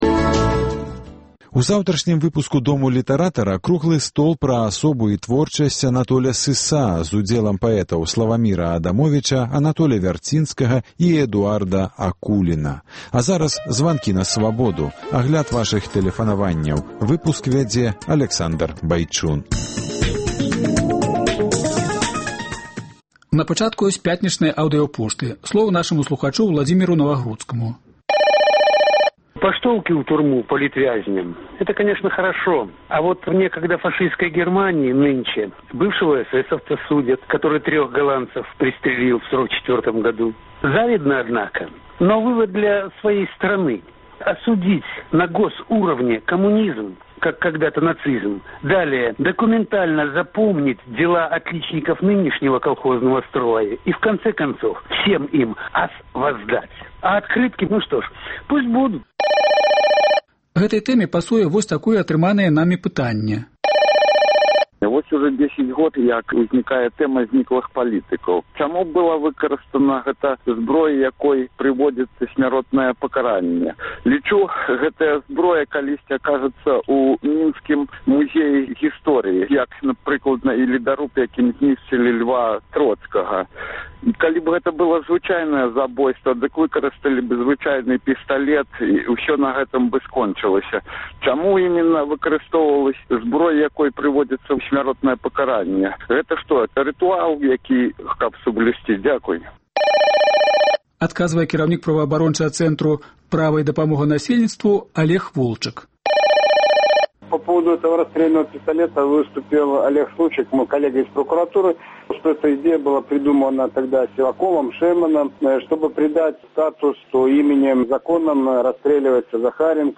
У сёньняшніх “Званках на Свабоду” — ня толькі тэлефанаваньні за пятніцу, але й тыя, якія мы не пасьпелі агучыць цягам тыдня, а таксама найбольш адметныя рэплікі, меркаваньні, пытаньні, што ўжо гучалі ад мінулага панядзелка на нашых хвалях.